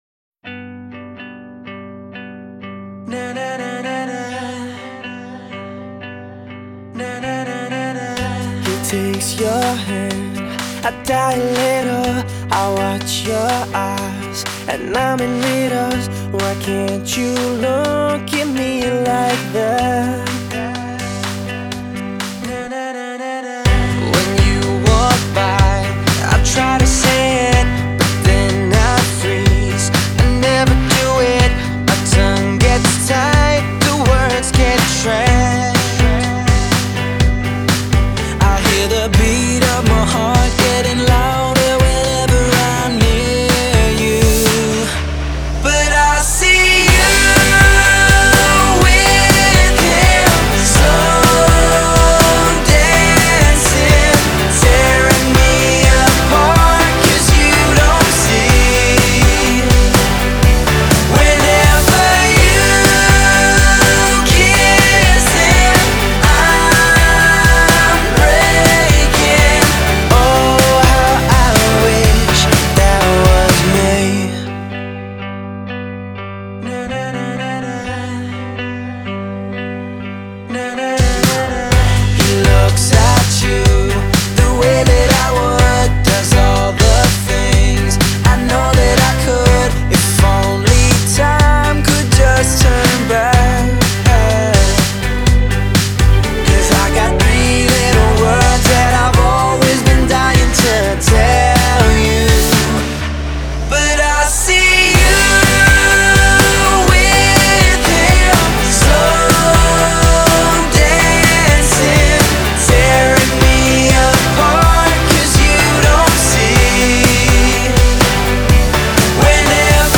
ژانر : پاپ